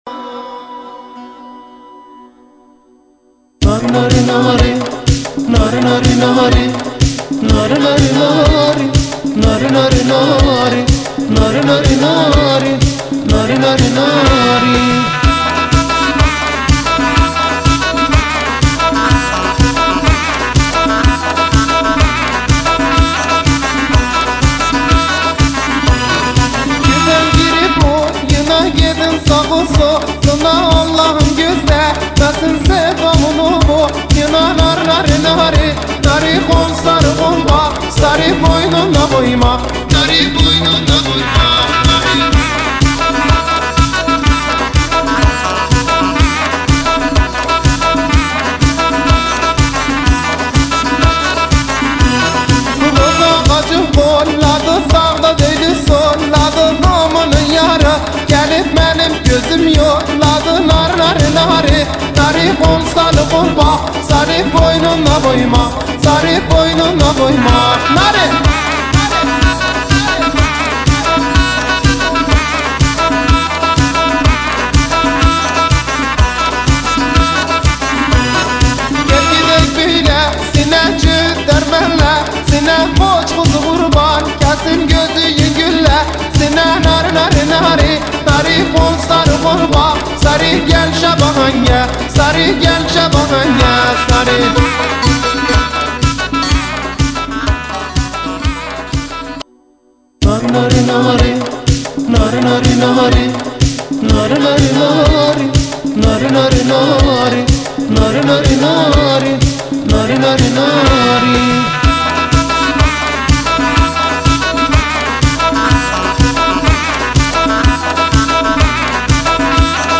دانلود آهنگ محلی
موزیک شاهسون